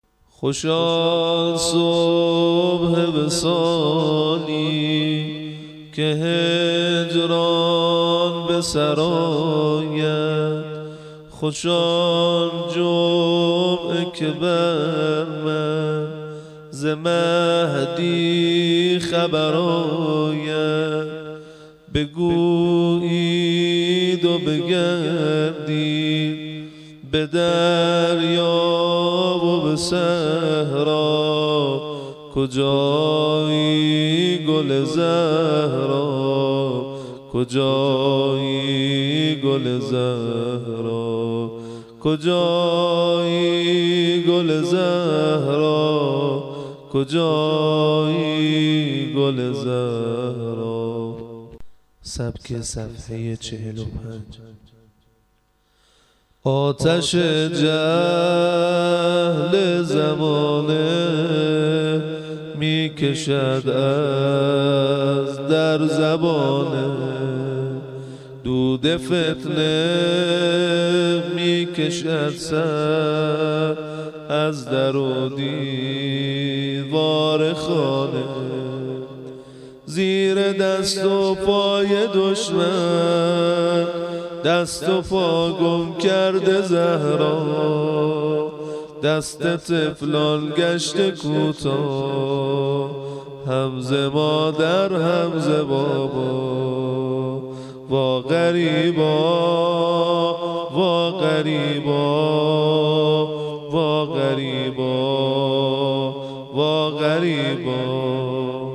ادای حق نمک ( نوحه )